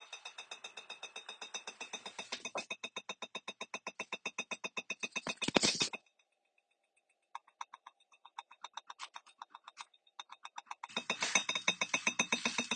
vibration_noise.m4a